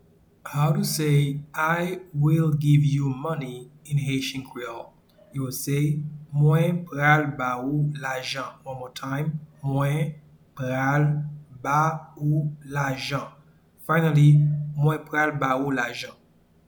Pronunciation and Transcript:
I-will-give-you-money-in-Haitian-Creole-Mwen-pral-ba-ou-lajan.mp3